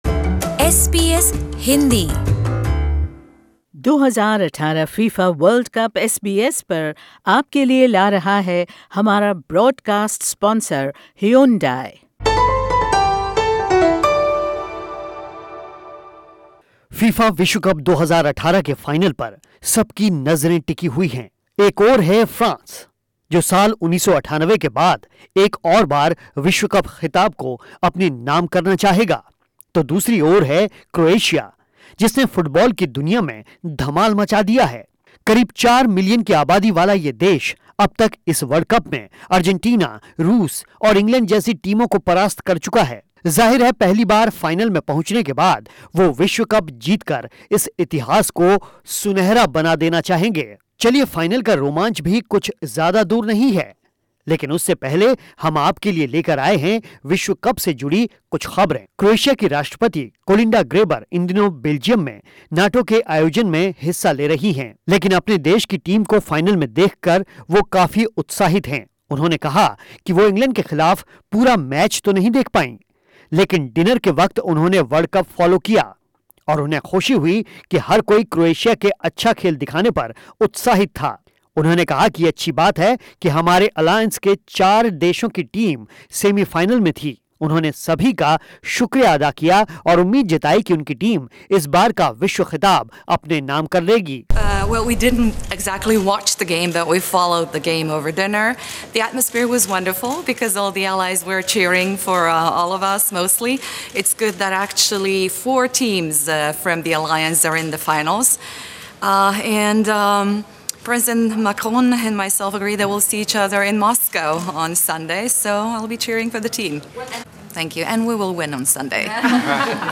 फाइनल से पहले कुछ और ख़ास खबरों पर सुनिए ये रिपोर्ट